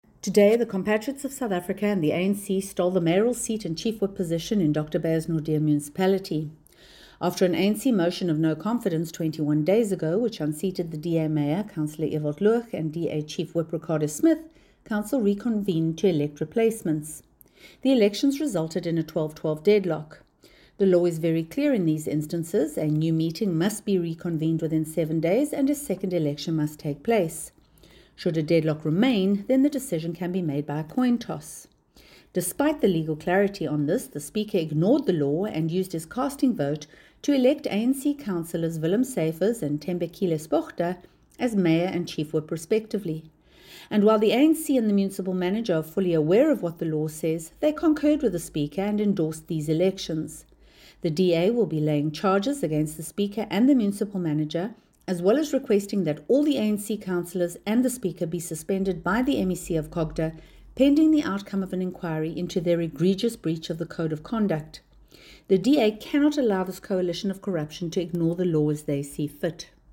Note to Editors: Please find attached a soundbite in
English by Samantha Graham-Maré, MP.